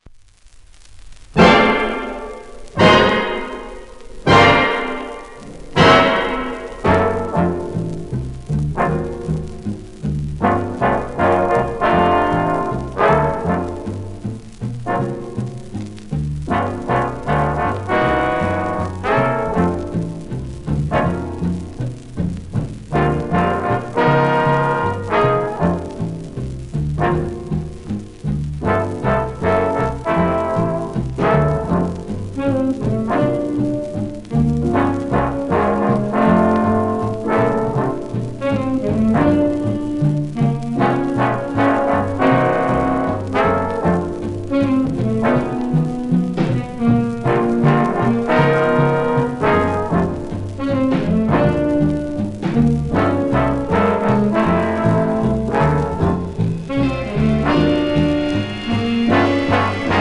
1951年録音